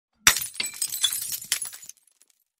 Звуки шампанского
Разбили бокал вдребезги на счастье